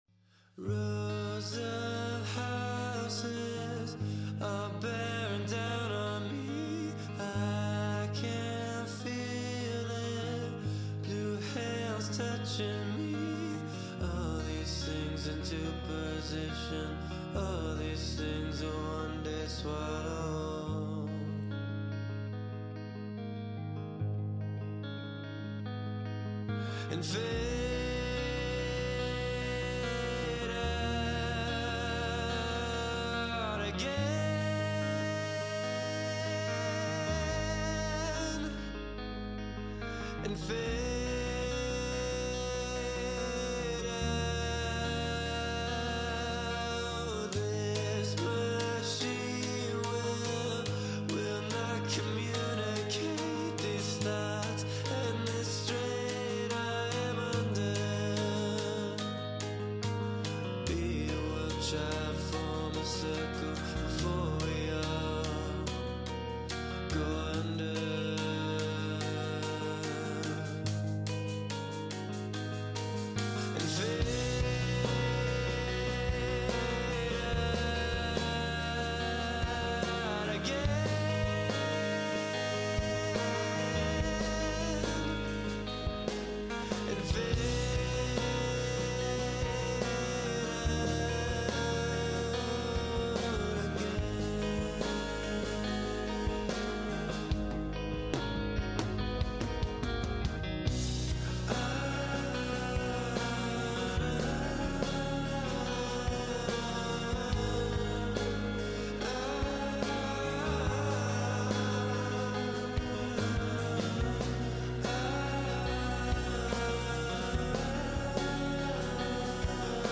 main guitar